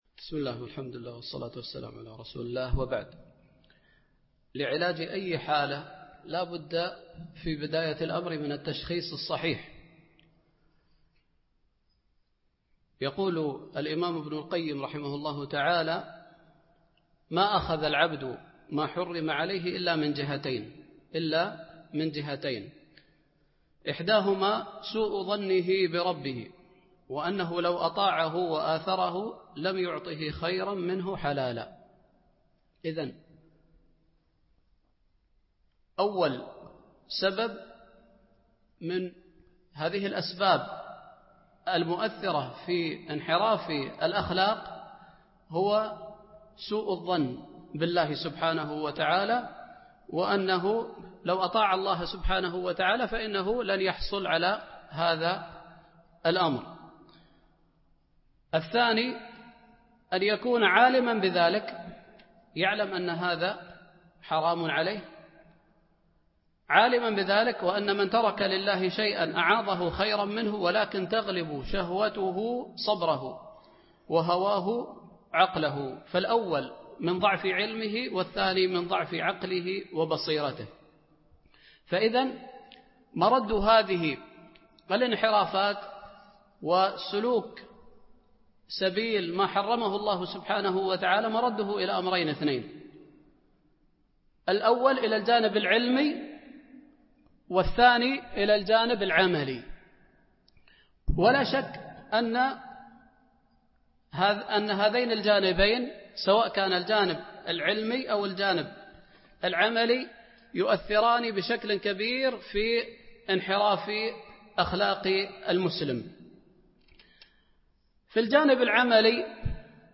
majmoueat-machaikhe-nadwa-ilmiya-7-aleinhirafe-aleakhlaki-inda-achababe-asbabe-wa-ilaje-10.mp3